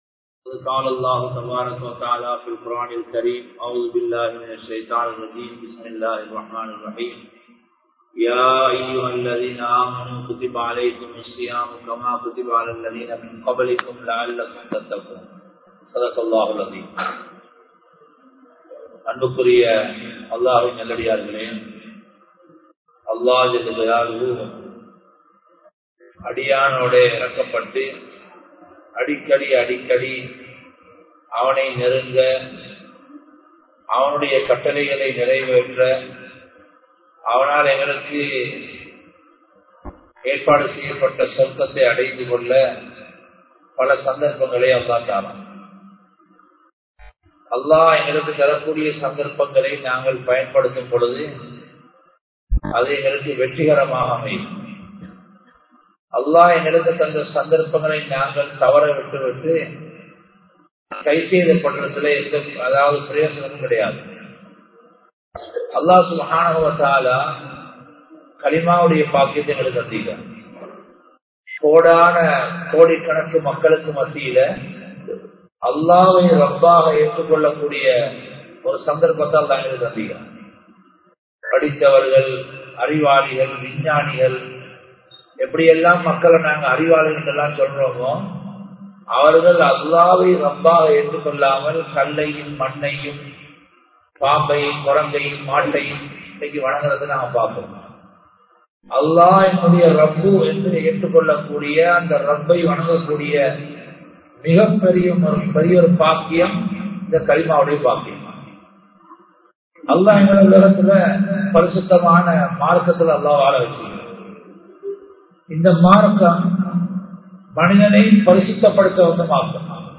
நோன்பை வீணடிக்காதீர்கள் | Audio Bayans | All Ceylon Muslim Youth Community | Addalaichenai
Nooraniya Jumua Masjidh